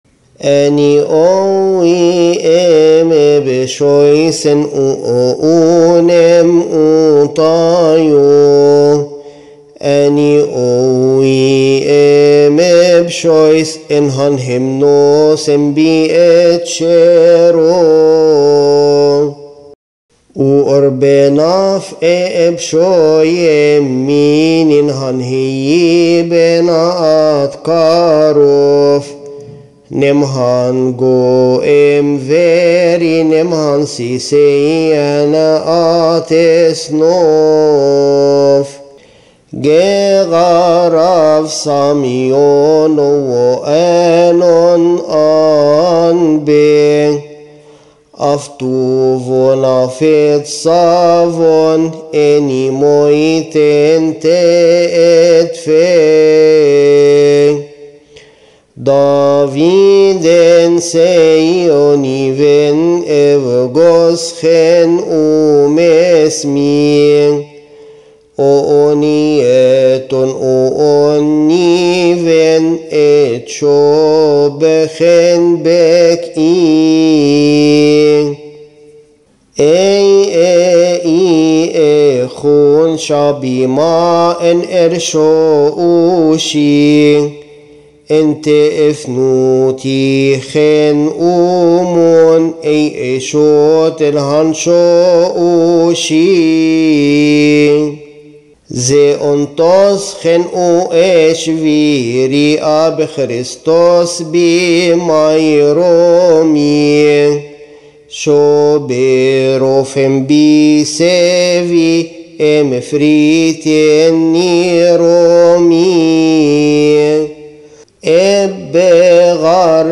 المرتل
إبصالية آدام